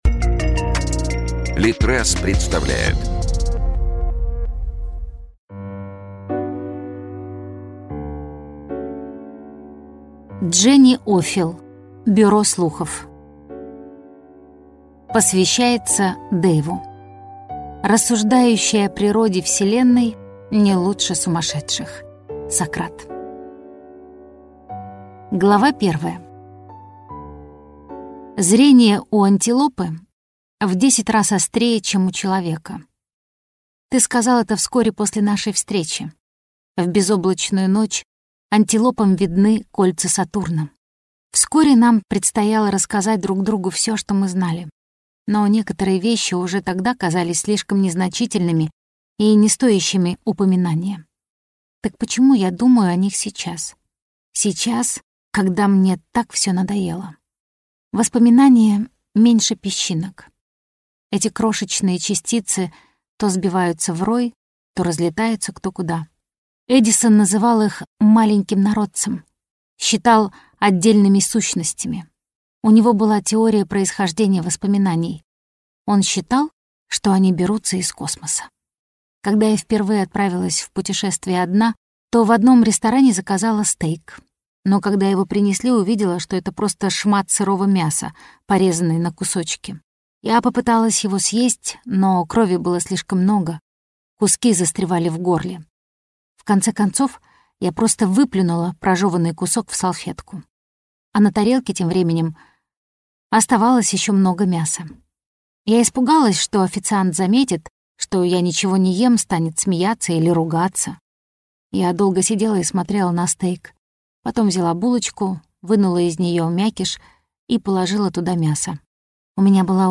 Аудиокнига Бюро слухов | Библиотека аудиокниг